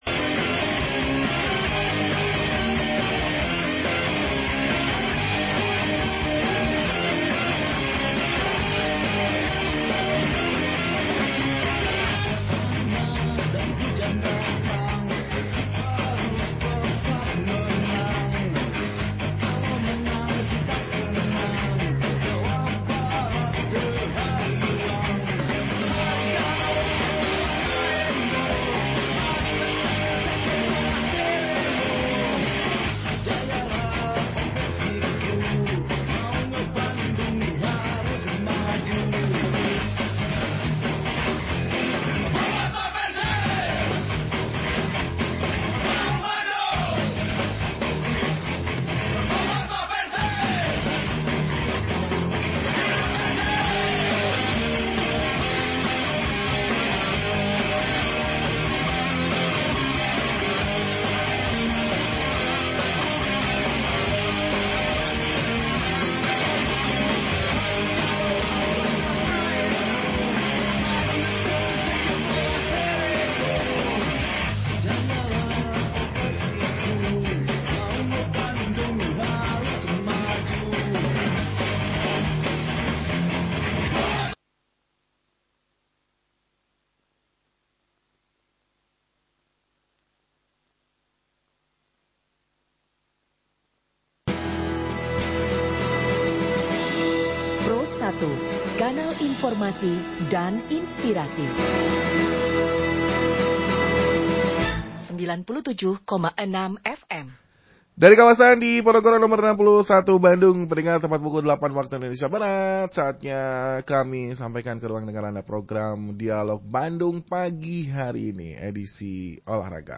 Rekaman siaran